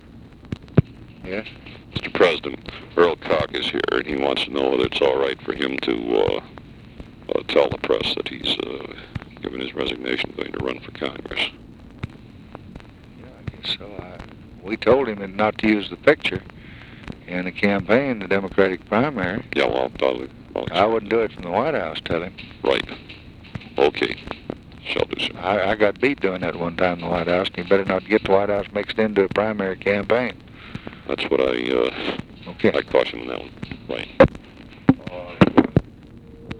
Conversation with GEORGE REEDY, May 2, 1964
Secret White House Tapes